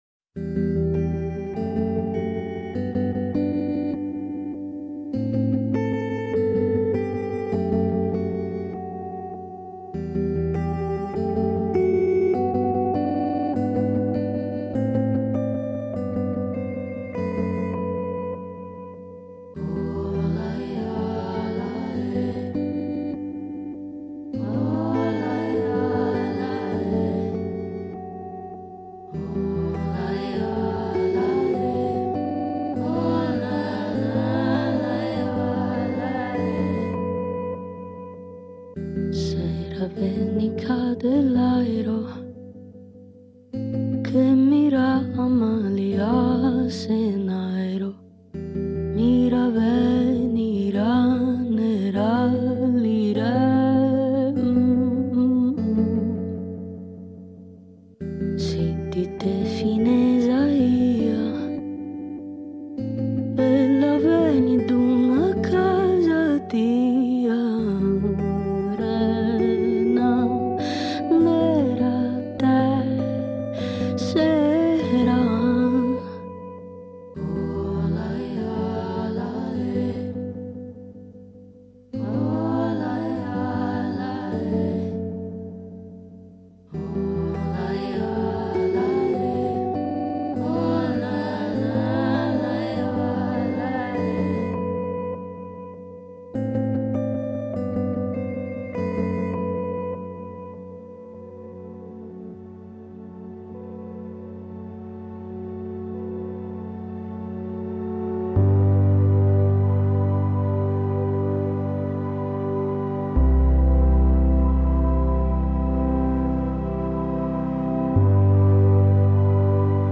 Il disco, incentrato su enigmatiche e suggestive esecuzioni vocali, tessute su pattern elettronici e linee di chitarra acustica, cantate in una lingua inesistente, un mix di antiche parole galluresi, frammenti di termini italiani e vocaboli totalmente inventati, è anche il vincitore del Premio Tenco 2023 nella categoria “migliore opera prima”. Un disco che suona insieme arcaico e futuristico: la sperimentazione vocale si stende sui tappeti elettronici trasfigurando quello che potrebbe essere un primitivo canto popolare, e rendendolo assai più simile a una romanza futuribile.